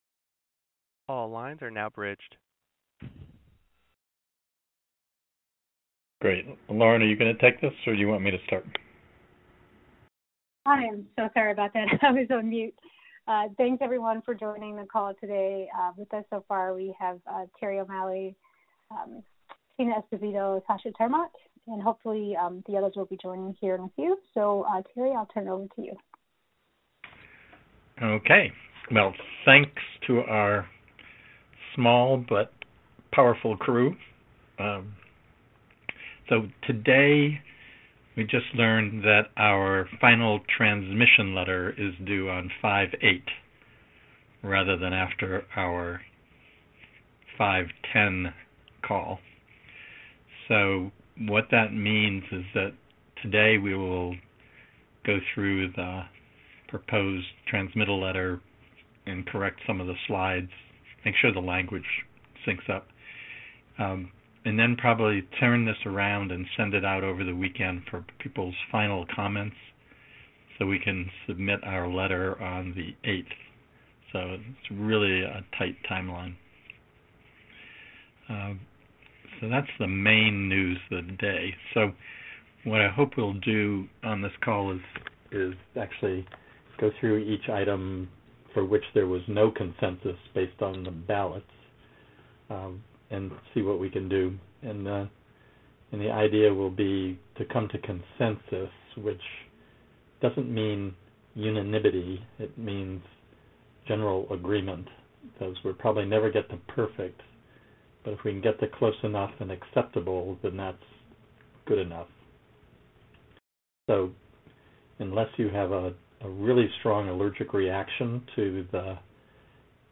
U.S. Core Data for Interoperability (USCDI) Task Force Meeting Audio 5-3-2019